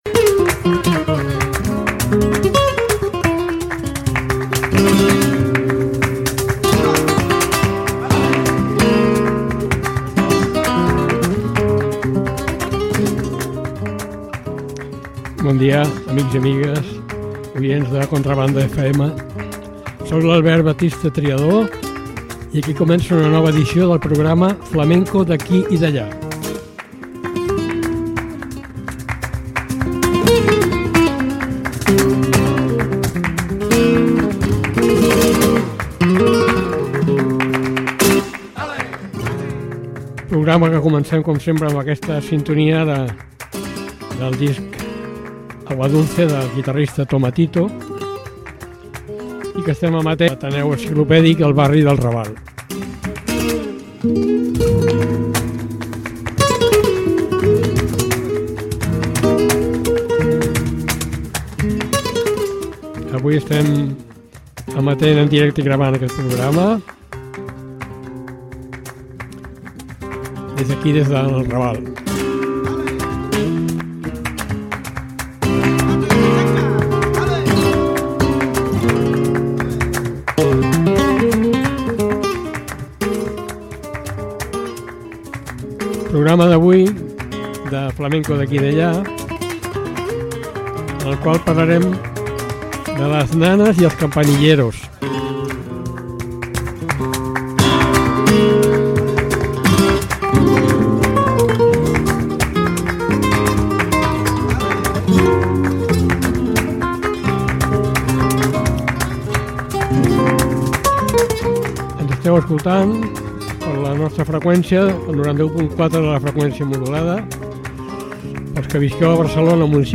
Dos estils flamencos especials.